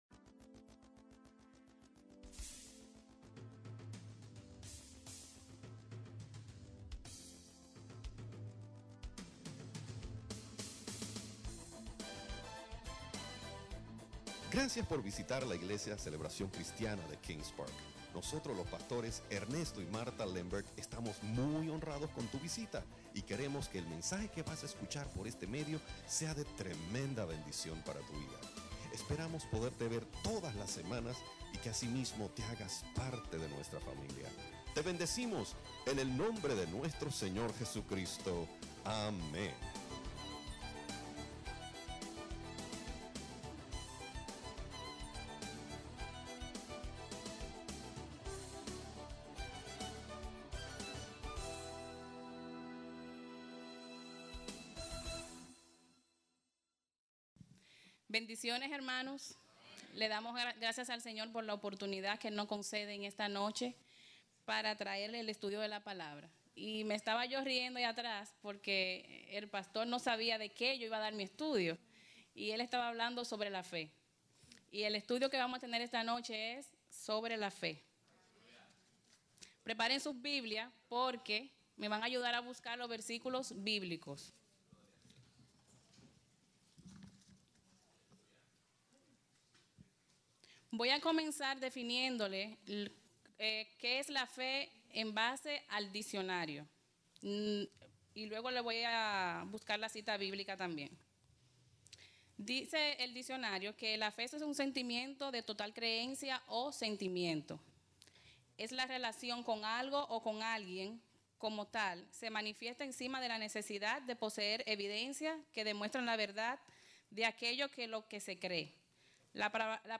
Estudio Bíblico